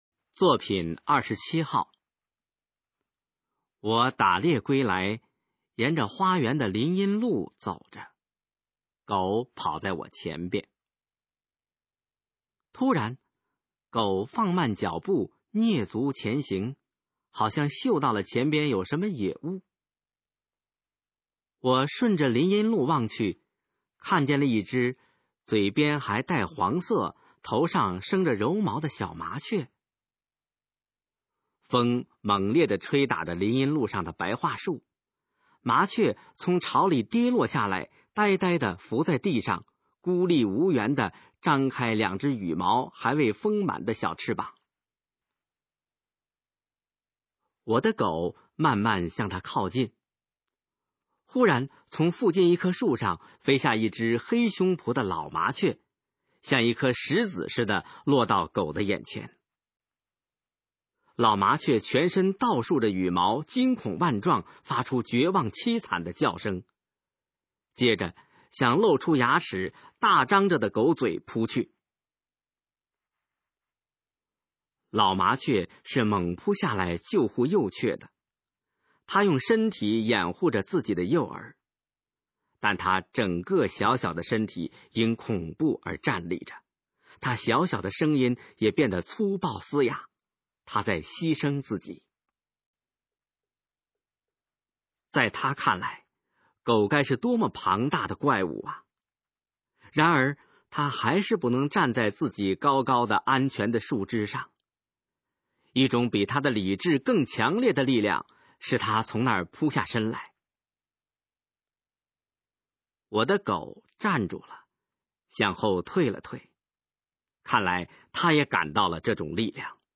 首页 视听 学说普通话 作品朗读（新大纲）
《麻雀》示范朗读_水平测试（等级考试）用60篇朗读作品范读